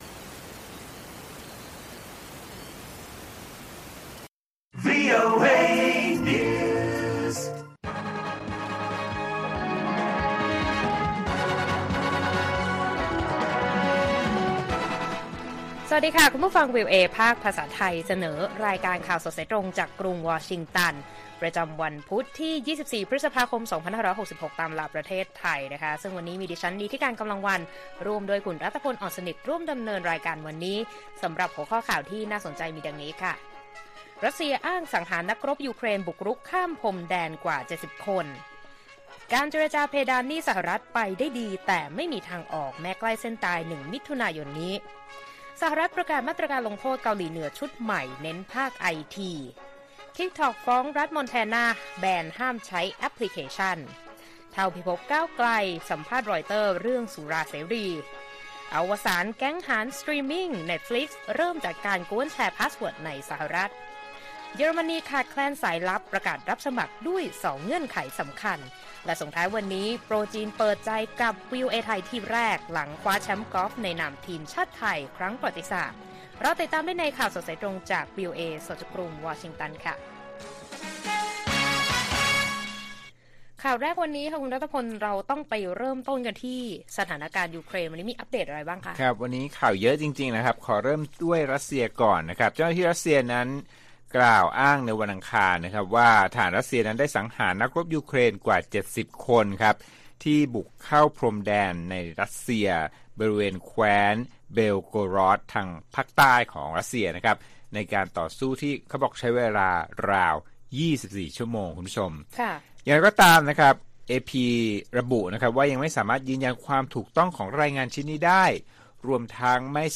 ข่าวสดสายตรงจากวีโอเอไทย พุธ ที่ 24 พ.ค. 66